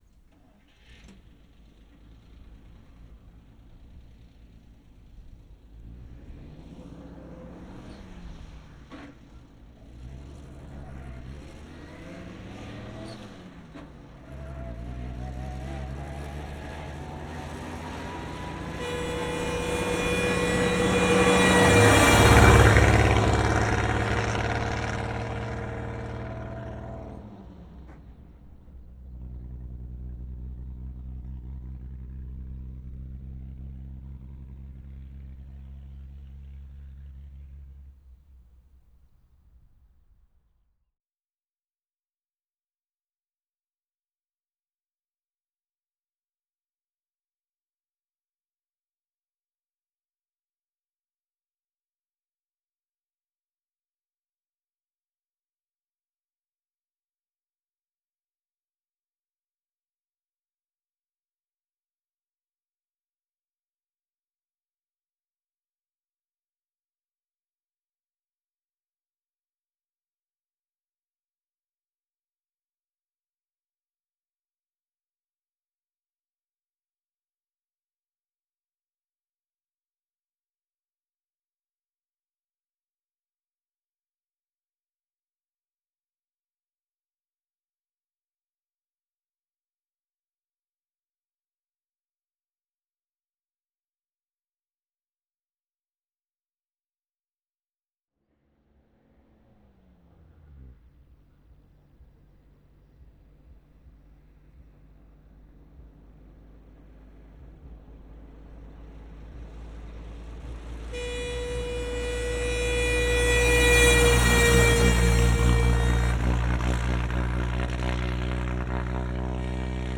opel_blitz_t14_ext_fast_bys_horn_position_b_MKH416.wav